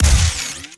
GUNTech_Sci Fi Shotgun Fire_07_SFRMS_SCIWPNS.wav